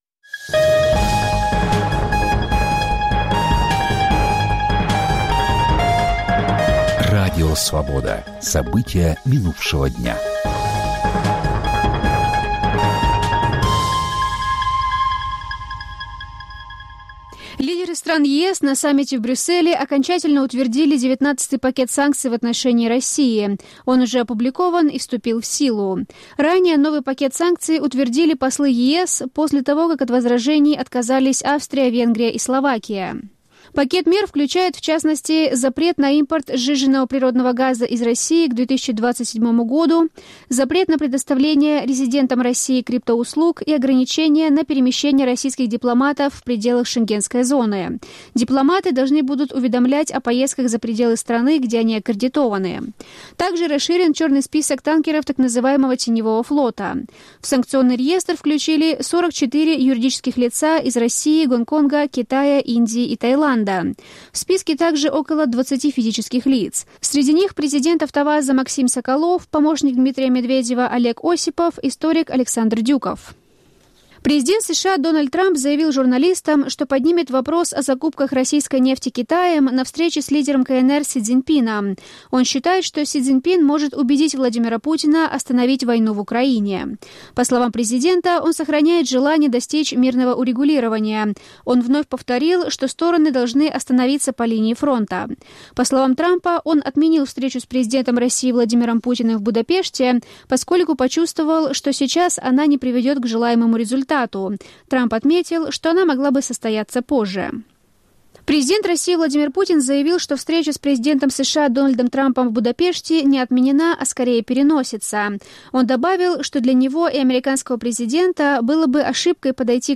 Аудионовости
Новости Радио Свобода: итоговый выпуск